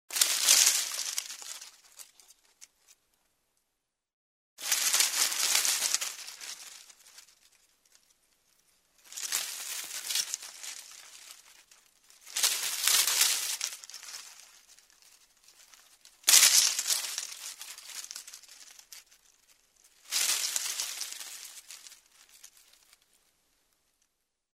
Звуки сухих листьев
Шелест листьев под ногами